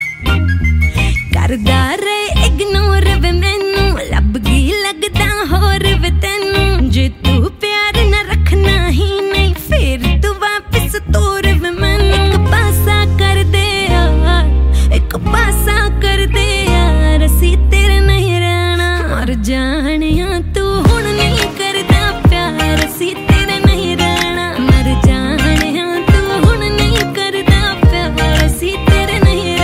Category: Bollywood Ringtones